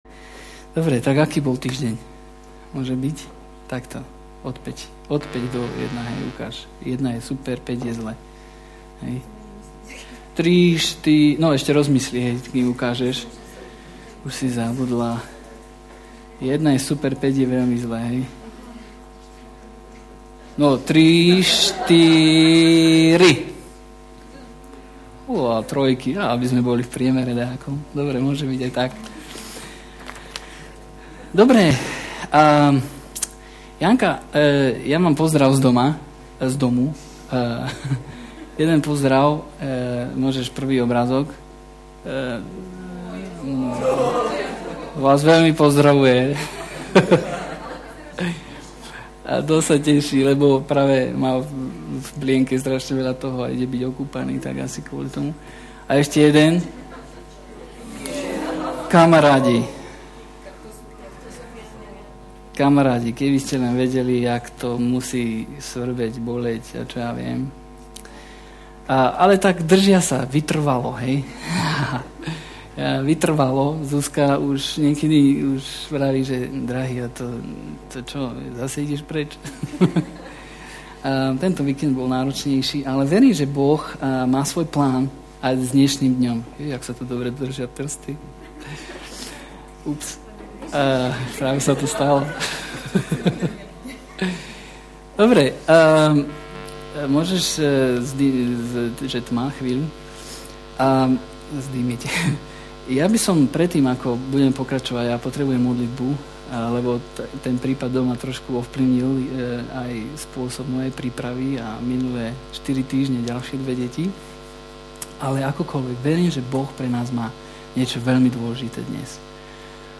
Nahrávka kázne Kresťanského centra Nový začiatok z 2. marca 2008